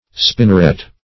Spinneret \Spin"ner*et\ (sp[i^]n"n[~e]r*[e^]t), n. (Zool.)